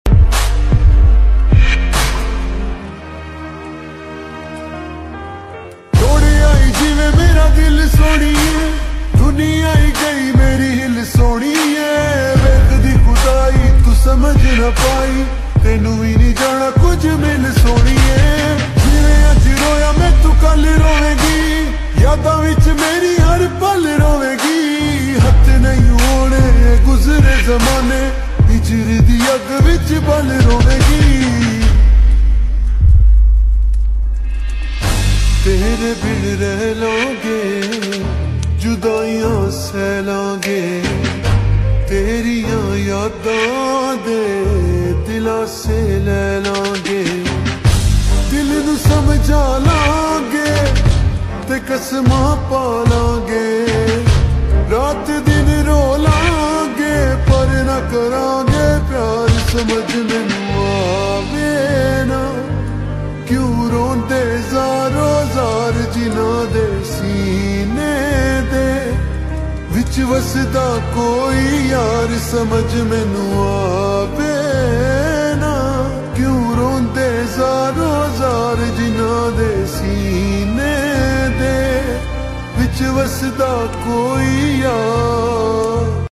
Full Sad Song